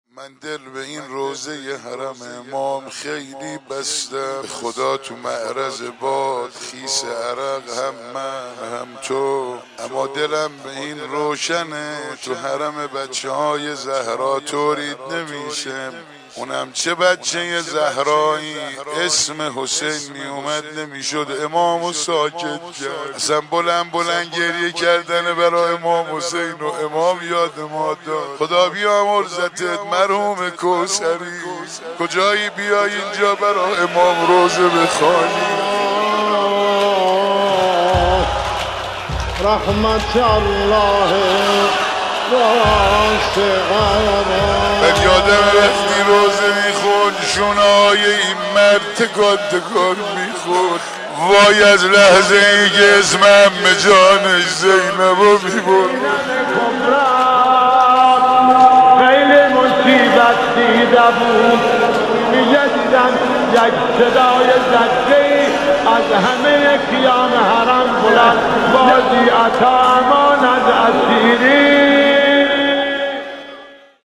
مداحی
هیئت رزمندگان اسلام مرقد مطهر امام خمینی (ره)